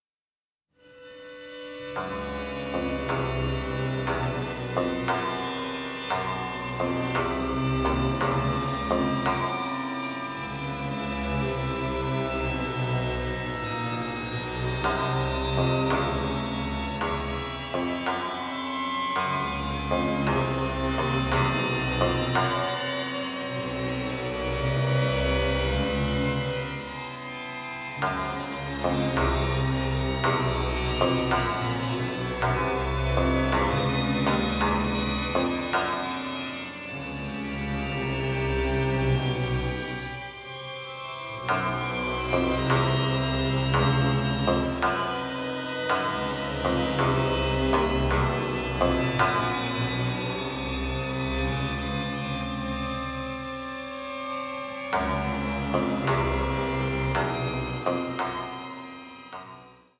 in full stereo for the first time.
the melancholic, the macabre and the parodic